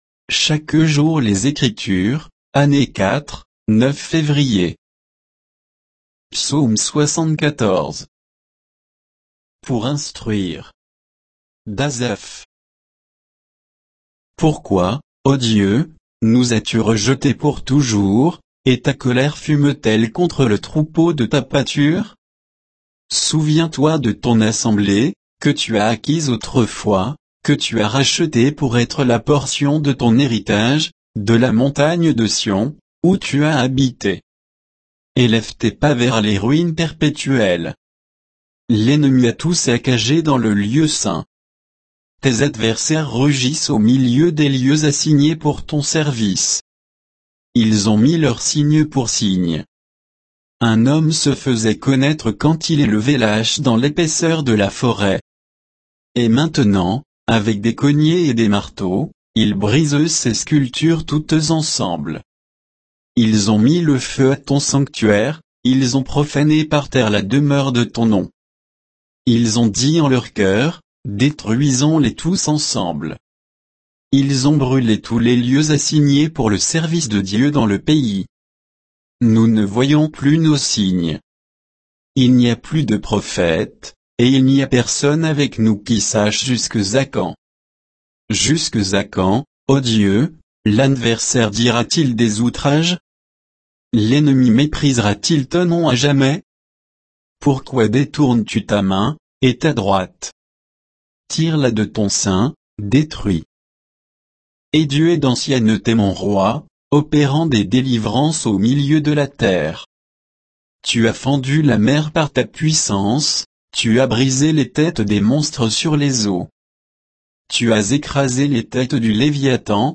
Méditation quoditienne de Chaque jour les Écritures sur Psaume 74